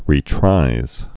(rē-trīz)